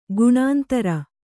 ♪ guṇāntara